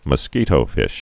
(mə-skētō-fĭsh)